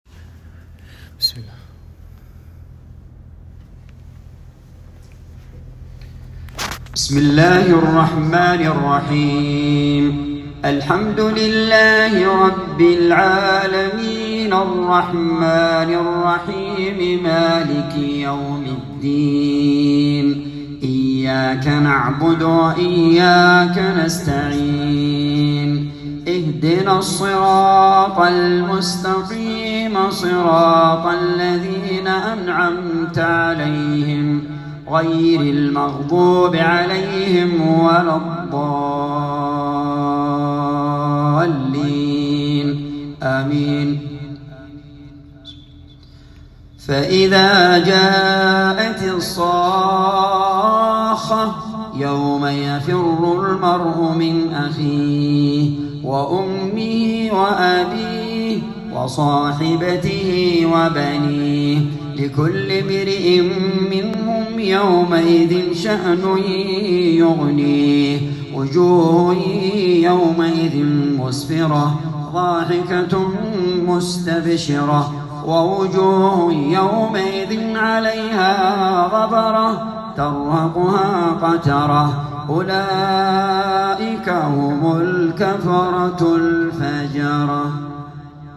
تلاوة من سورتي الفاتحة وعبس